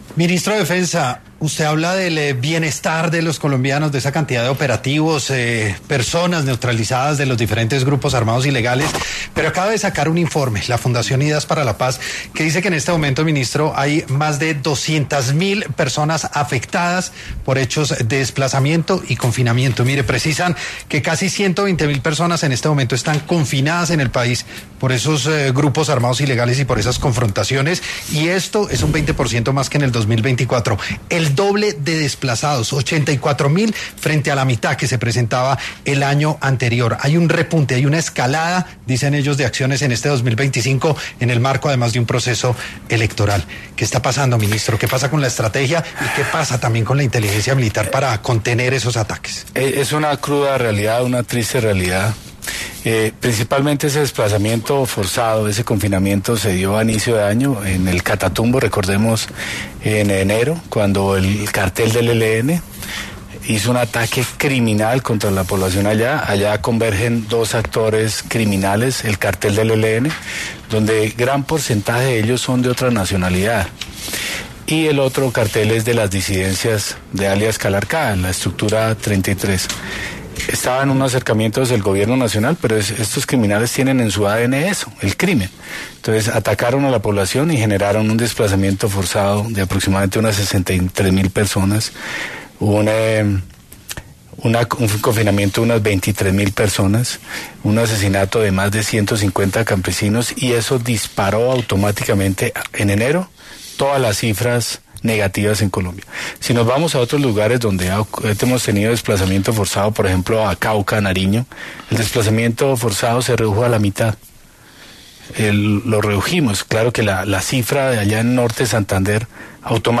El ministro de Defensa, Pedro Sánchez, habló ‘Sin Anestesia’ en ‘La Luciérnaga’ sobre desplazamiento forzado, confinamiento y los ataques con drones.
En este contexto, el ministro de Defensa, Pedro Sánchez, pasó por los micrófonos de ‘La Luciérnaga’ para abordar temas asociados al desplazamiento forzado, confinamiento y ataques con drones.